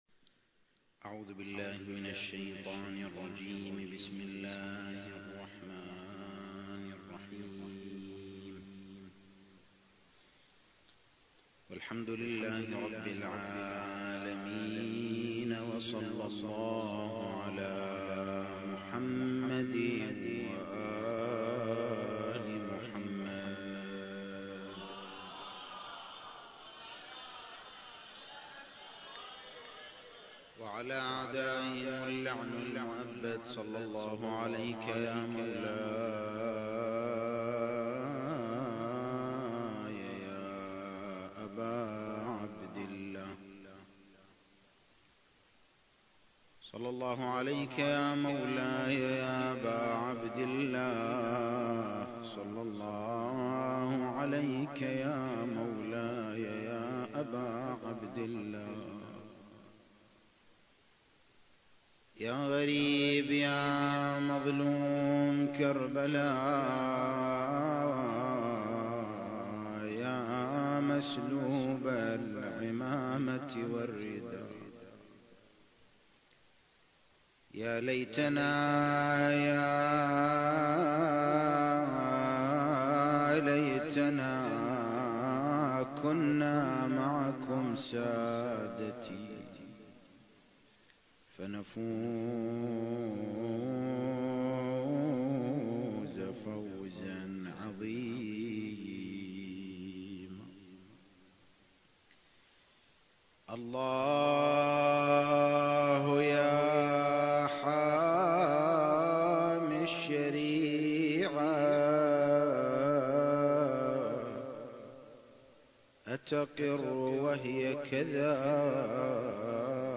المكان: حسينية الإمام الحسن (عليه السلام)/ الشارقة مجلس ادارة الأوقاف الجعفرية بالشارقة التاريخ: 1444 للهجرة